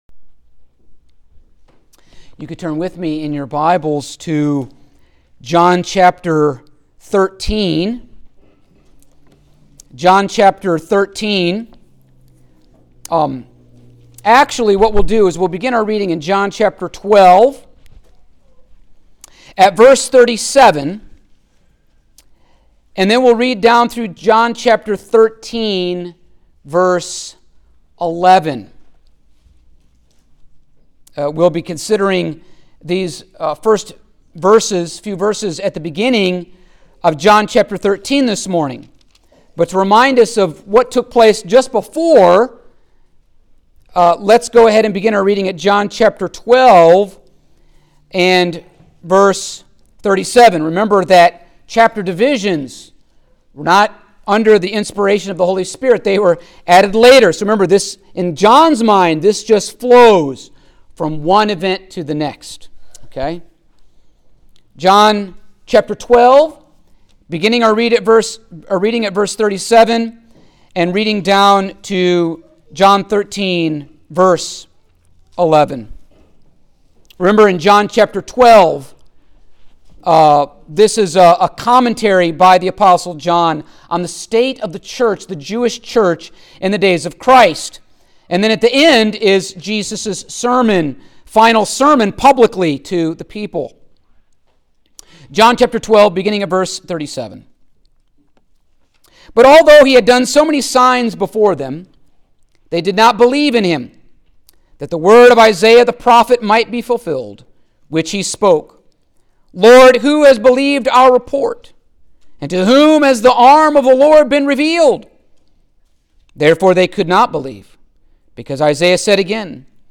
Service Type: Sunday Morning